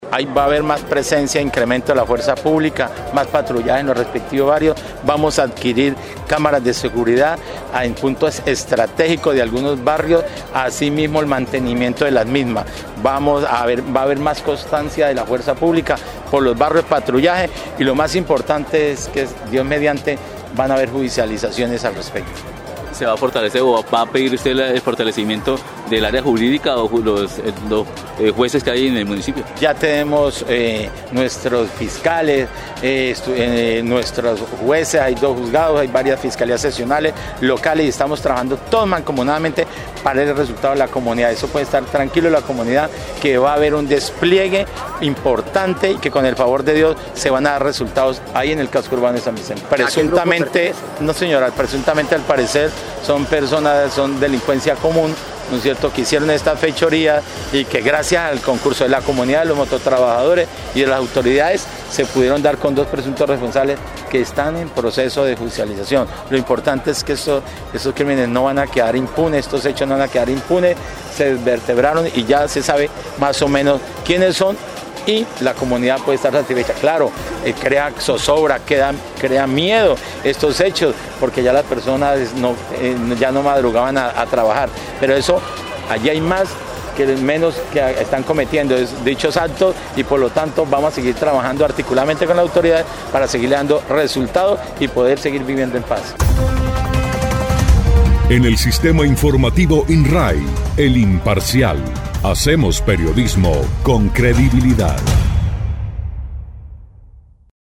Luis Trujillo Osorio, alcalde del municipio de San Vicente del Caguan, explicó que estas medidas se dan como respuesta a los hechos de alteración del orden público sucedidos durante las últimas semanas.
03_ALCALDE_LUIS_TRUJILLO_SEGURIDAD.mp3